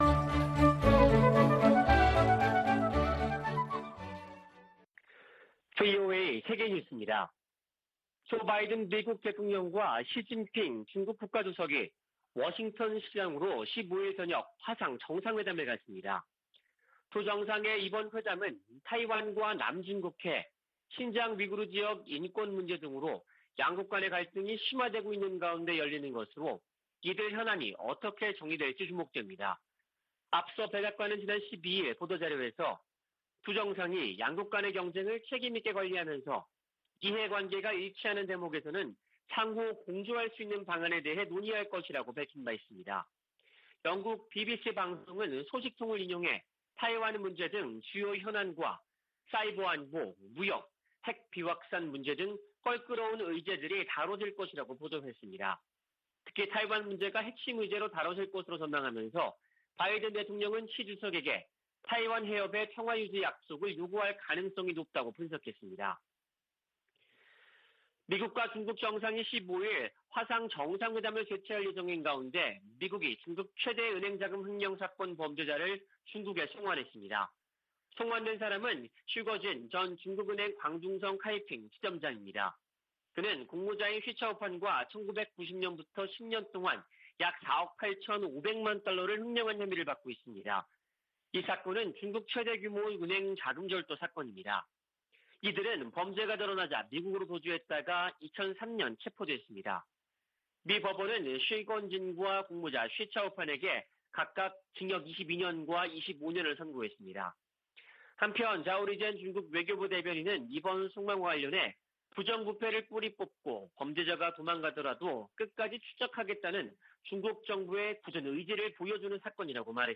VOA 한국어 아침 뉴스 프로그램 '워싱턴 뉴스 광장' 2021년 11월 16일 방송입니다. 미국과 한국 사이에 종전선언 논의가 이어지는 가운데 북한이 유엔군사령부 해체를 연이어 주장하고 있습니다. 미 국무부 동아시아태평양 담당 차관보는 미국이 북한에 전제조건 없는 대화 제안을 했으며, 북한의 답을 기다리고 있다고 말했습니다.